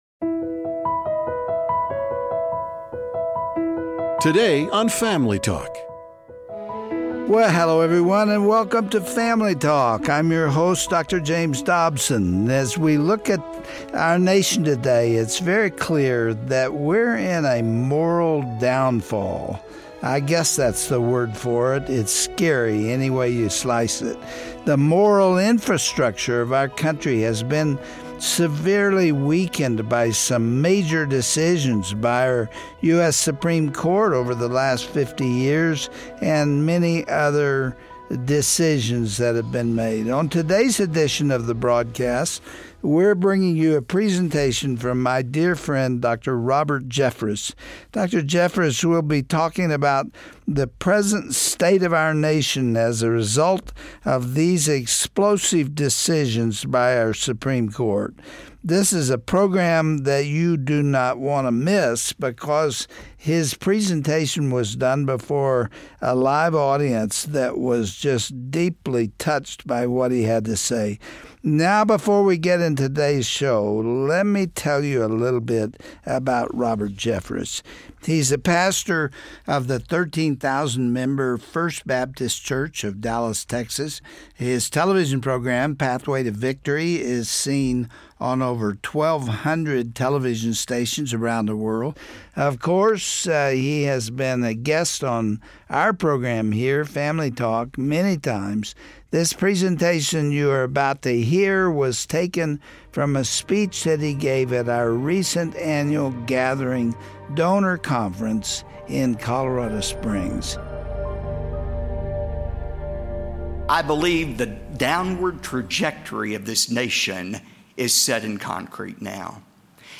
Christians around the country need to take a stand against the evil that is running rampant throughout society. Today on Family Talk, Dr. Robert Jeffress addressed a recent conference, hosted by Dr. Dobson, about the importance for Christ followers to fight for religious freedoms and turn the culture back to God.